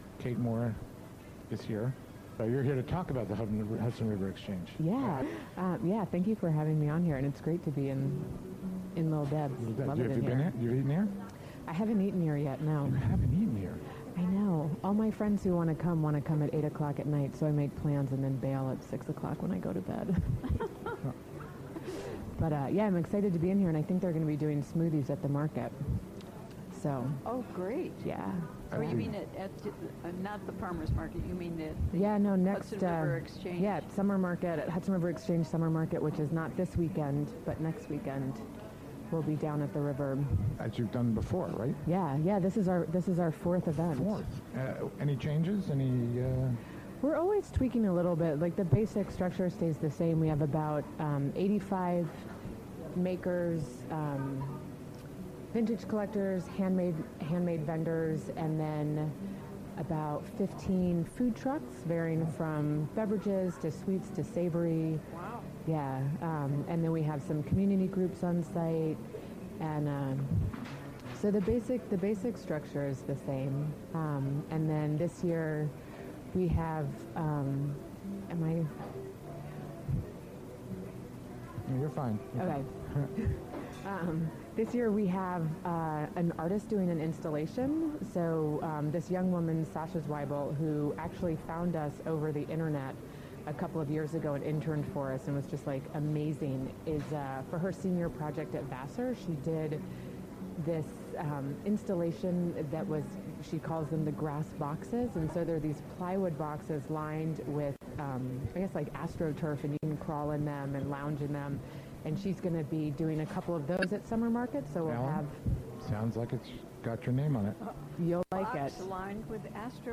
Special Remote Broadcast: Jun 16, 2016: 4pm - 6pm
Recorded during the WGXC Afternoon Show special "All Together Now!" Pledge Drive remote broadcast from Lil' Deb's Oasis in Hudson on Thursday, June 16, 2016.